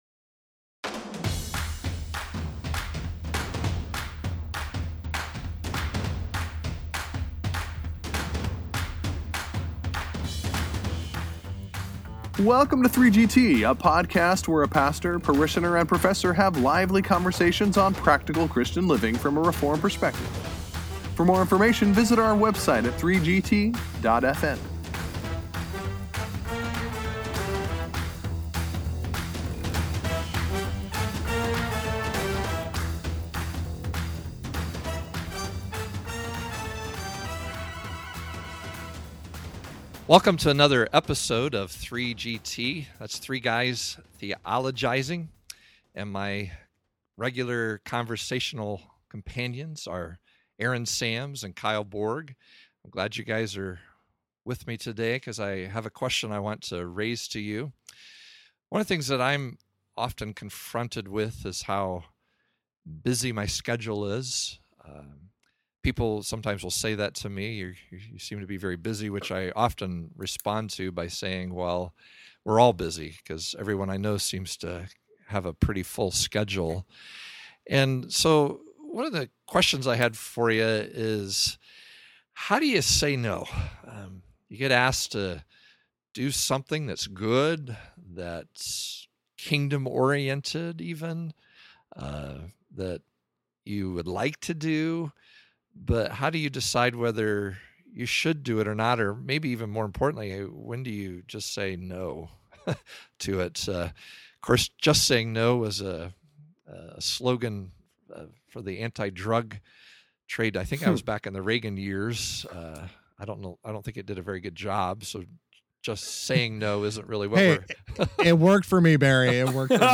As the three guys begin discussing this topic, one confesses he is a member of Yes Anonymous. Another has fun shouting “No!”
Hope you won’t say “No” to this energetic episode of 3GT!